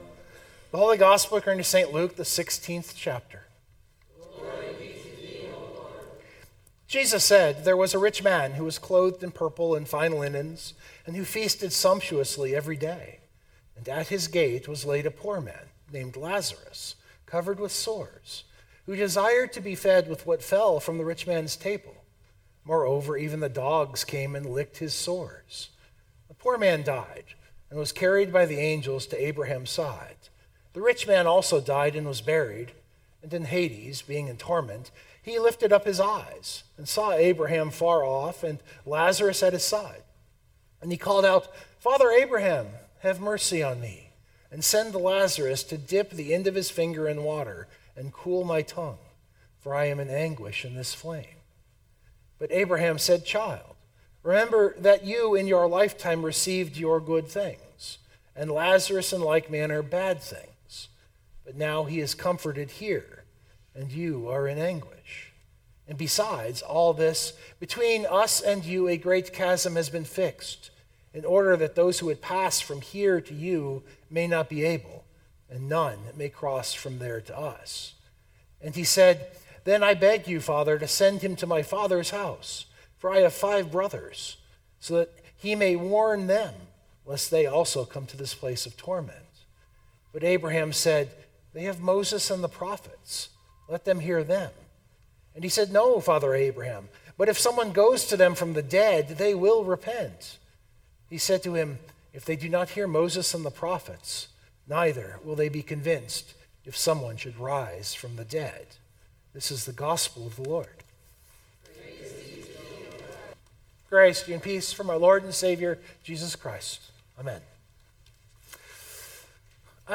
092825 Sermon Download Biblical Text: Luke 16:19-31 People want to use this text to get glimpses or insights into the afterlife.